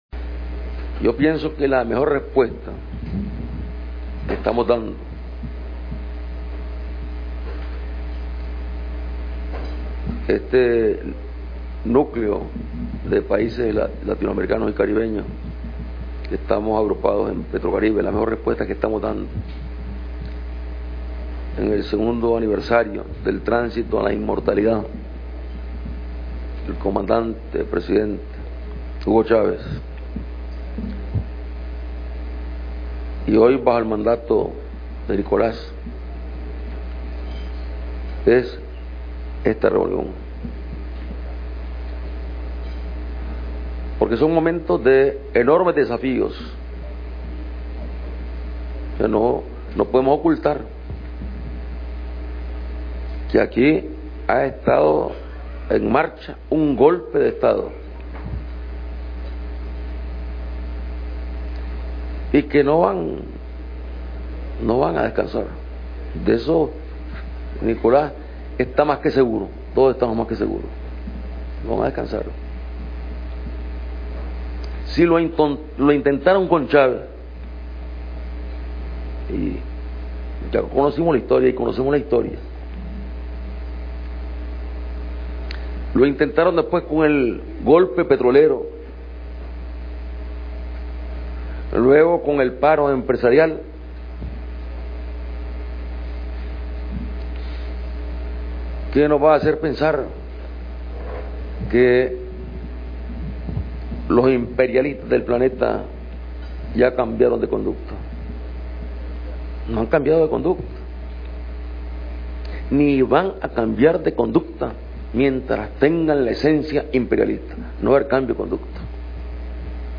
El Presidente de Nicaragua, Comandante Daniel Ortega Saavedra, aseguró durante la IX Cumbre Extraordinaria de Petrocaribe realizada en Caracas, Venezuela, que Latinoamérica y El Caribe están caminando en un proceso de liberación sostenido en la voluntad de los pueblos.